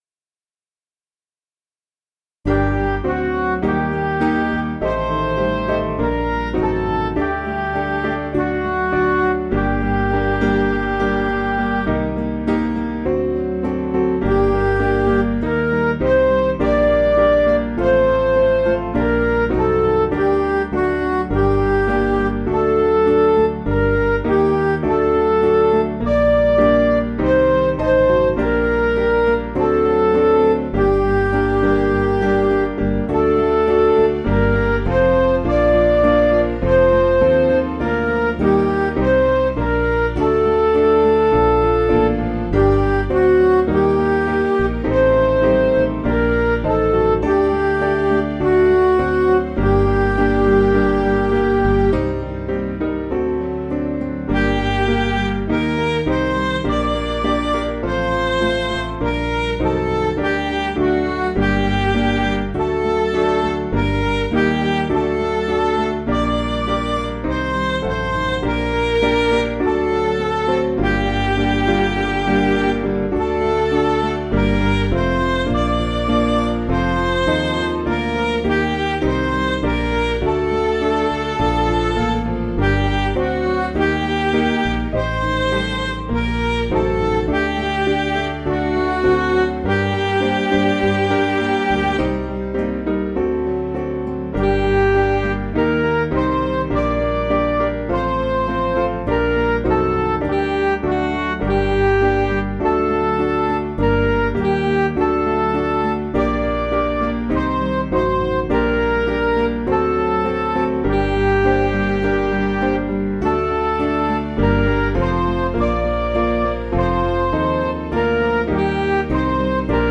Piano & Instrumental
(CM)   4/Gm
Midi